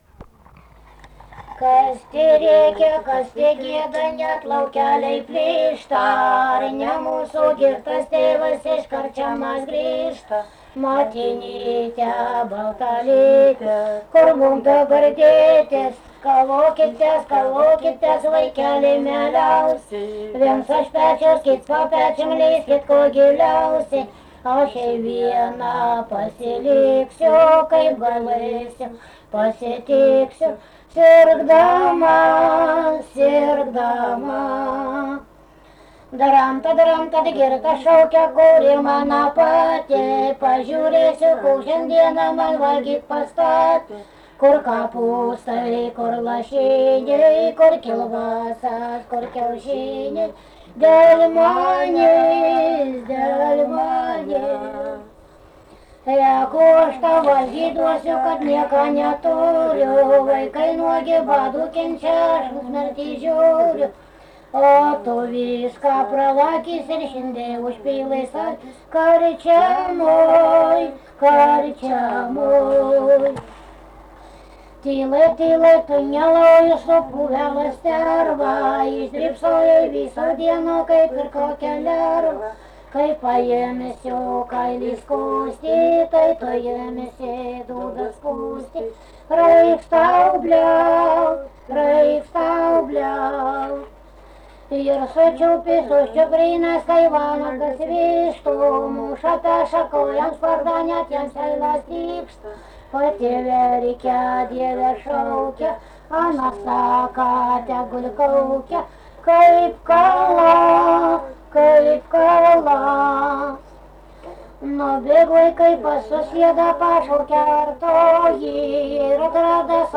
daina
Rageliai
vokalinis